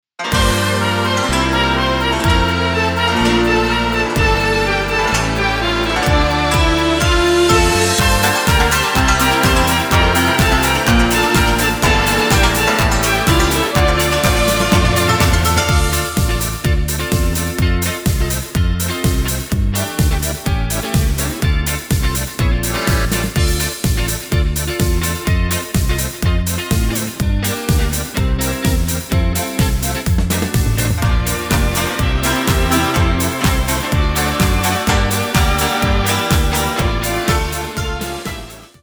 Hudobný podklad
Ľudové piesne